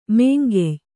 ♪ mēngey